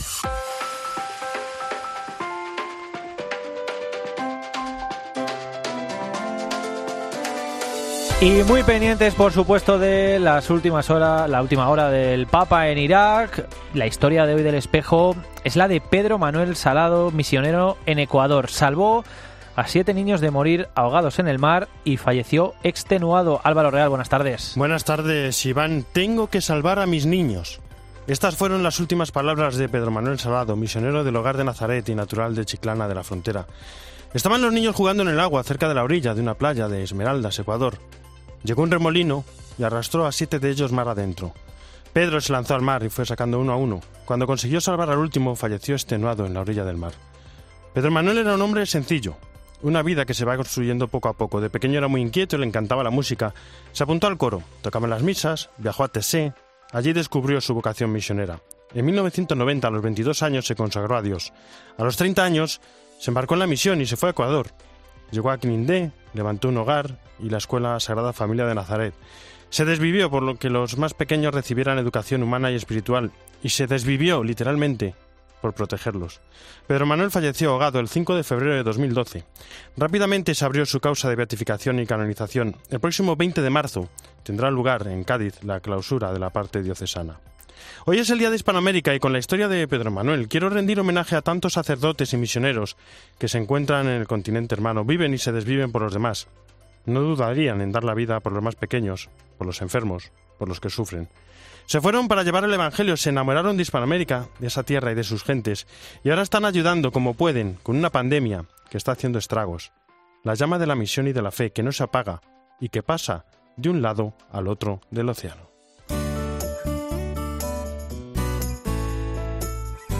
AUDIO: Hoy seguimos de cerca al Papa en Irak, además del Santoral, reportaje, entrevista e Hispanoamérica.